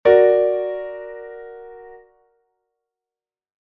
Que tipo de acorde estás a escoitar?
aumentado2.mp3